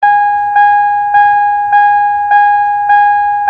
seatbeltbeep.ogg